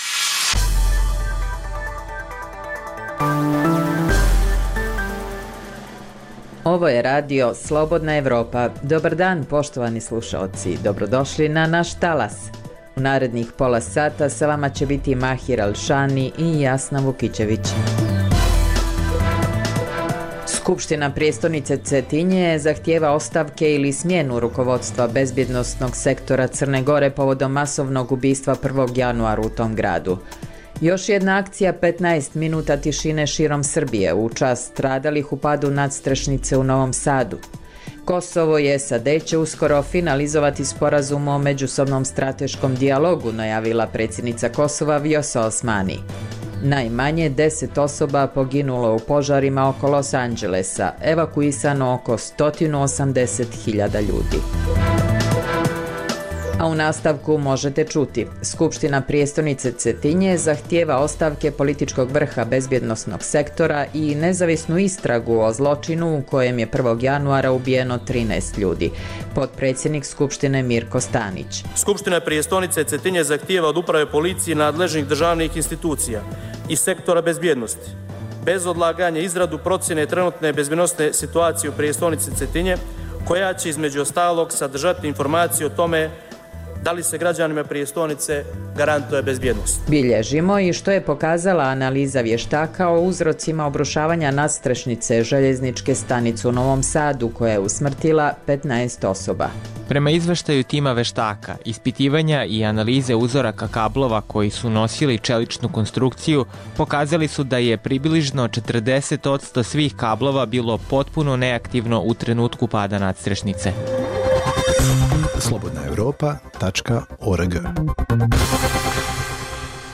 Daily News